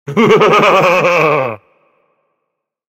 Maniacal Laugh Sound Effect
A deep, twisted laugh. Short, slightly maniacal, and eerie chuckling. Creepy laughing. Human sounds.
Maniacal-laugh-sound-effect.mp3